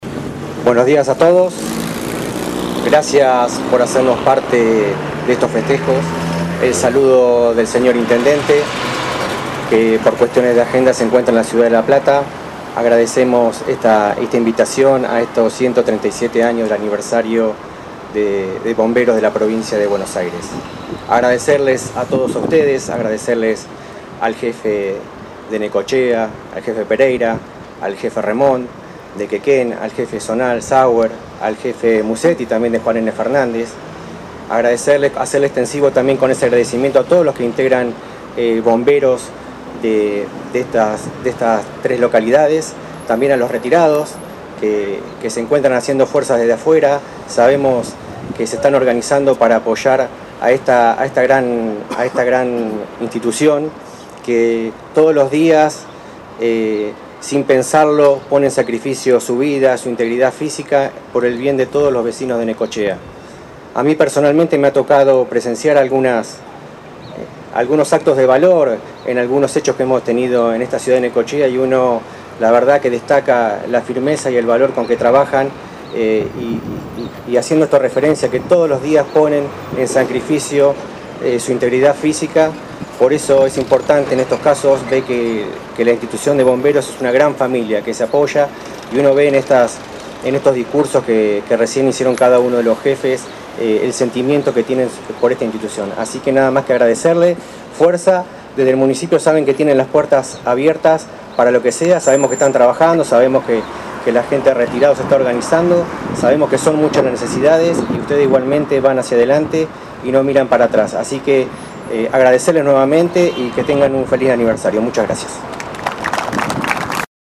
“Esta gran institución cuenta con personas que todos los días sin pensarlo pone en riesgo su vida por los vecinos de Necochea”, expresó el secretario de Gobierno Jorge Martínez al dirigir las palabras al pie del monumento de 42 y 59.